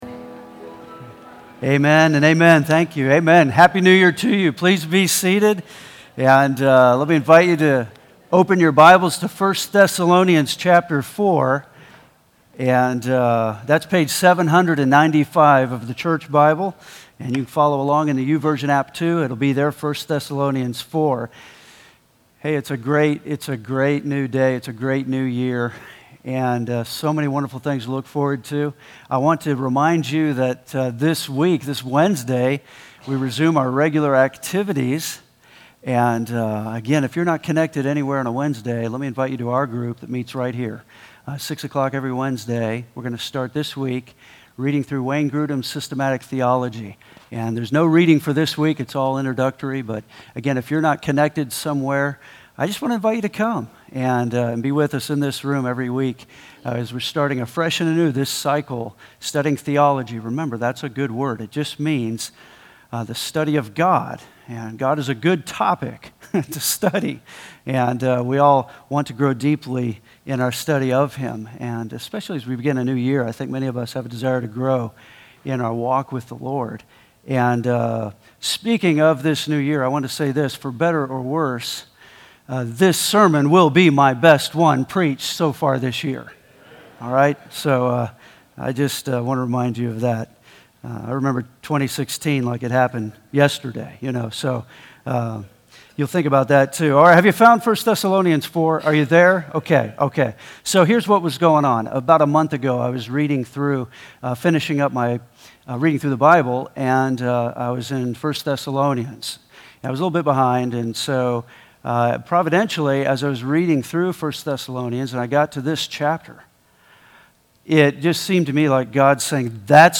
New Year’s Day